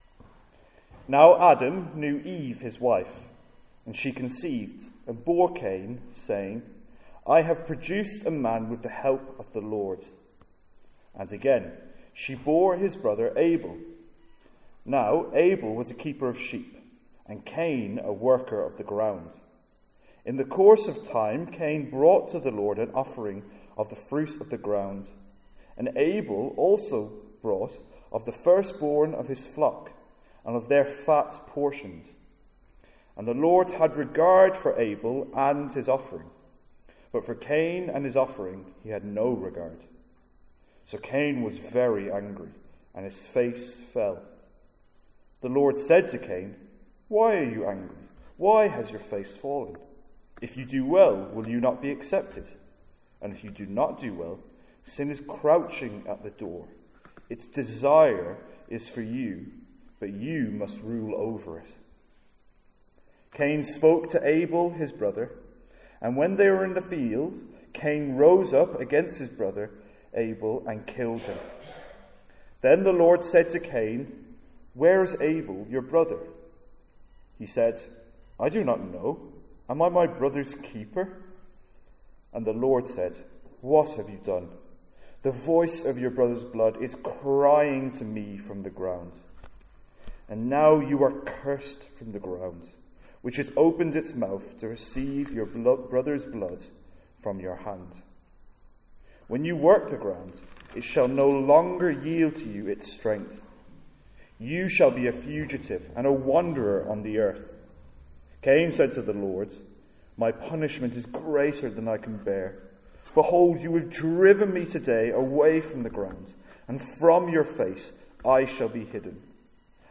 Sermons | St Andrews Free Church
From our evening series in Genesis.